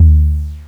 TM88 Mix808.wav